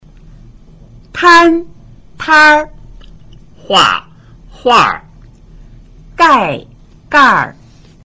攤 tn (動)    攤兒 tnr (名)
畫 hu (動)   畫兒 hur (名)
蓋 gi (動)    蓋兒 gir (名)